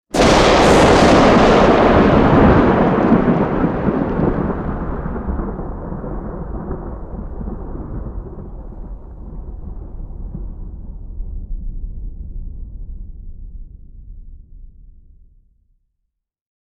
thunder_12.ogg